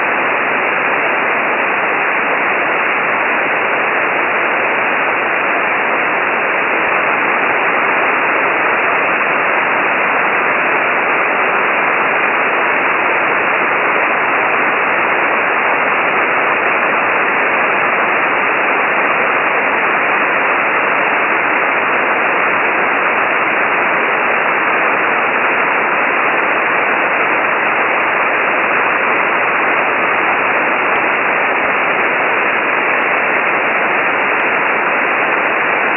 The record of the simulated aurora signal in ASK at 87 characters/minute with SNR=-11 dB is here: